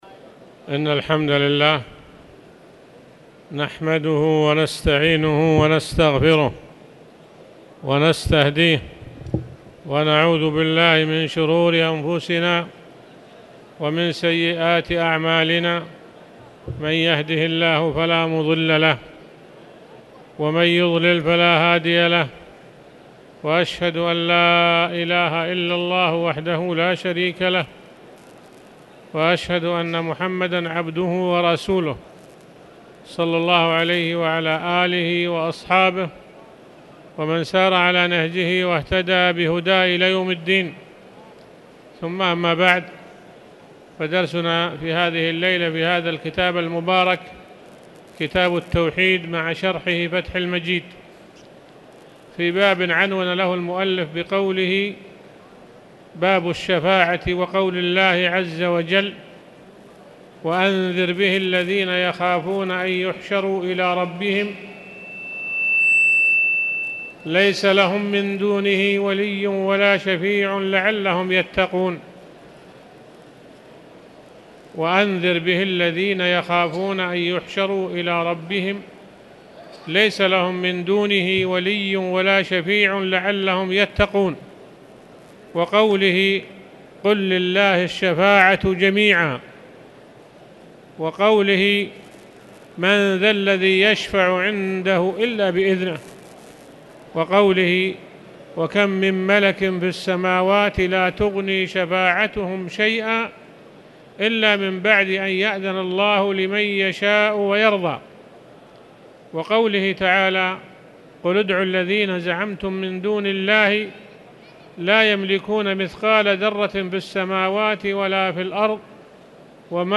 تاريخ النشر ٥ ربيع الثاني ١٤٣٨ هـ المكان: المسجد الحرام الشيخ